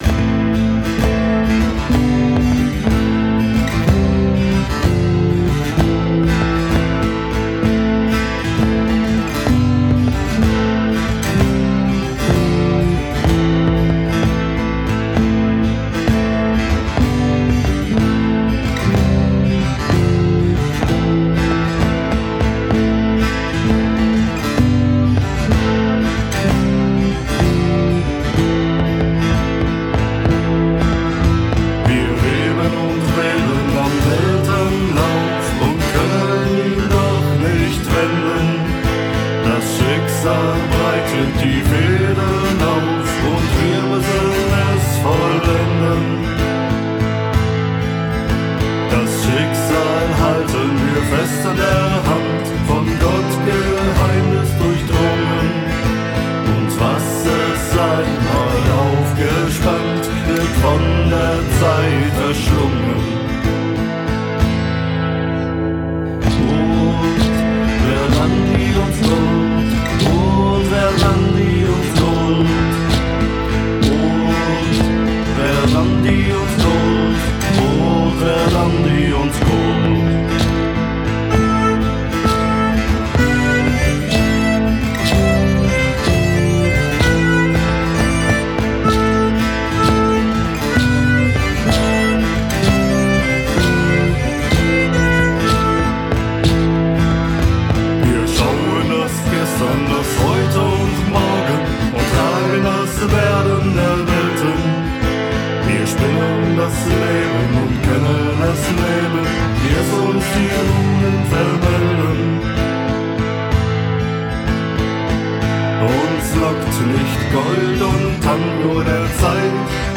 aber sicherlich nicht typischen Studioalbum zurück
Nachdenklich, mystisch und irgendwo auch verzaubernd.